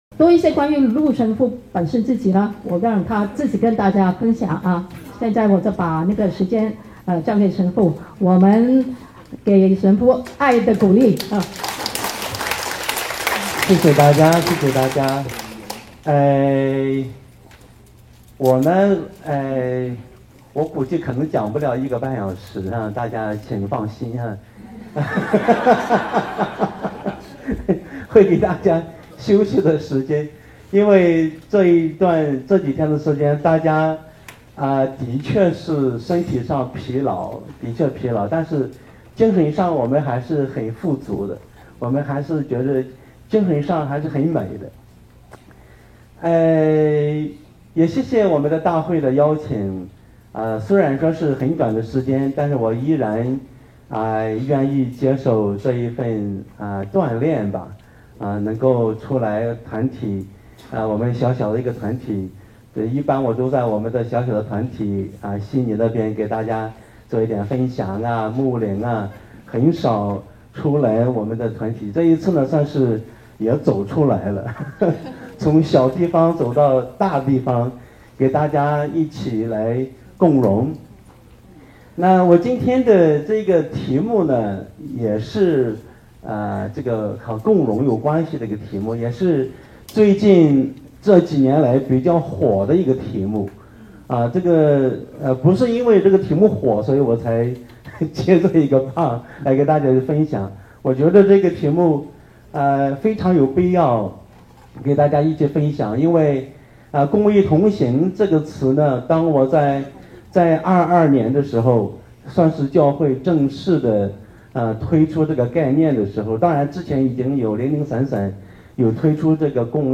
第12届世界华语圣经大会：讲座七